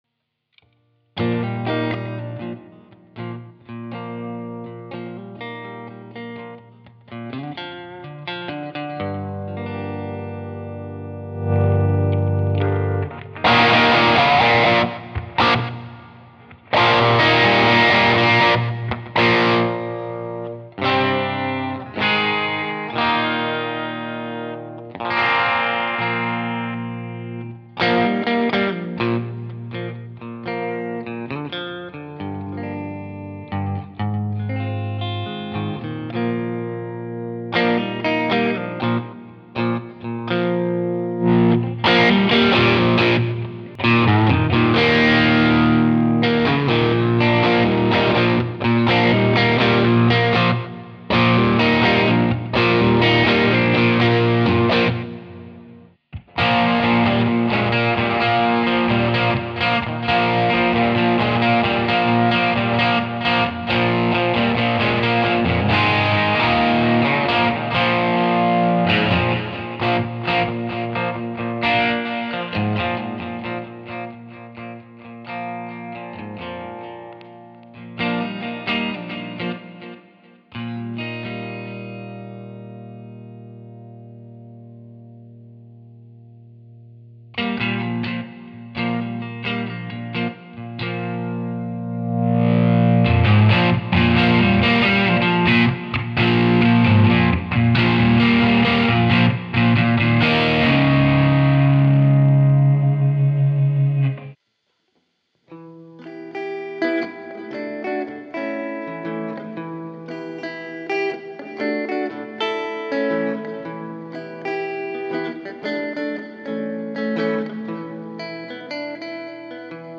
Ich habe noch mal ein paar Einstellungen hintereinander aufgenommen mit verschiedenen Cabs und Hall-Echo-EQ- und Pan-Einstellungen. Gitarre: Broadcaster aus dem CS bis auf den letzten Led Zepplinpart (Les Paul R8), ProCo Rat als Verzerrer vor dem Blues Deville Reissue. Volumenänderungen wurden nur während des Spielens am Volumenregler der Gitarre vorgenommen. Die Effekte sind alle aus der OX Amp Top Box. Aufnahme in Logic Prop X. In dieser Software habe ich keine weitere Bearbeitung durchgeführt. Kam alles so aus der Box Das ist schon super wie dynamisch alles auf das Spiel und den Volumenregler an der Gitarre reagiert - sehr flexibel, von Clean bis Crunch nur mit dem Volumenregler der Gitarren.